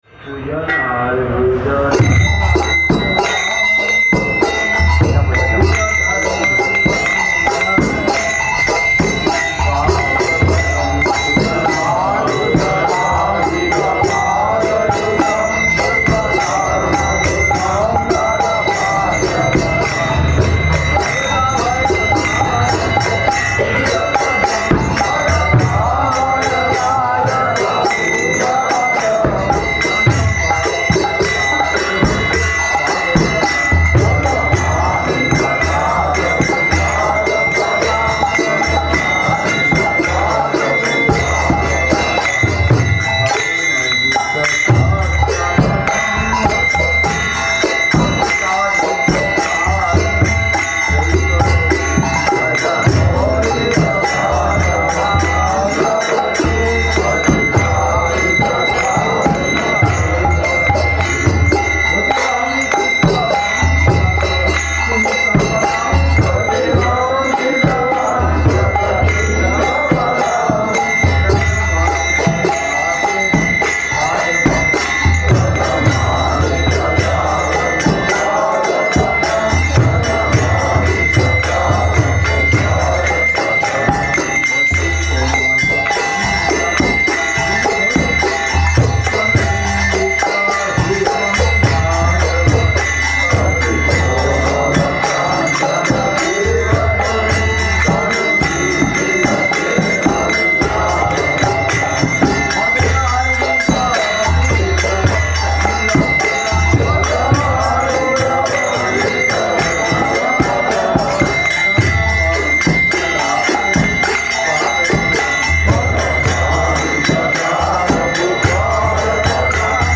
Gaura Purnima Parikrama 2012
Киртан